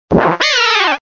Fichier:Cri 0432 DP.ogg